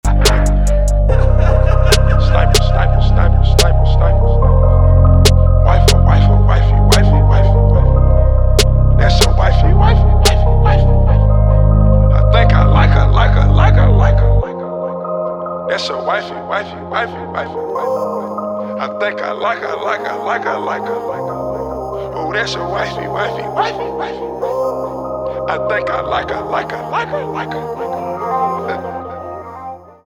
рэп
битовые , крутые , кайфовые , тихие , спокойные , хип-хоп